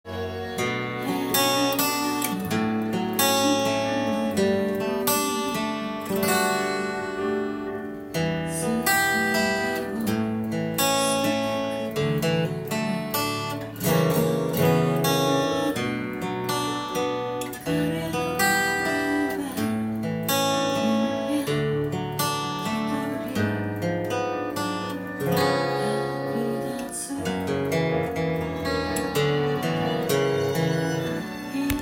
オリジナルtab譜　Aメロ
音源にあわせて譜面通り弾いてみました
２カポで弾くと音源にあわせてギターで弾くことが出来ます。
この曲は、バラード調のアレンジなのでアコースティックギターで弾く場合
アルペジオ奏法で弾くのが最適です。